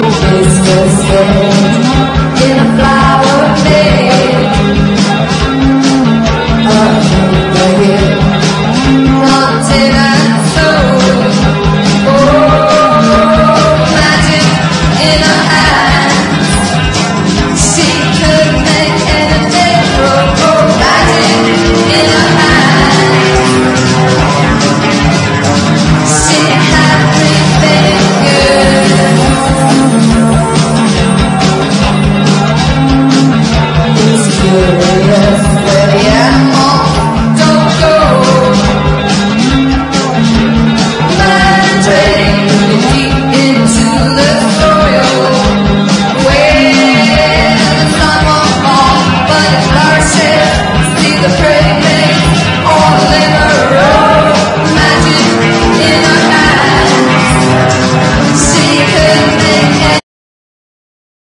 NEW WAVE / POST PUNK / GOTH
ダンサブルなゴシック・ニューウェイヴ！ アイルランドのサイケデリック・ゴス・バンド！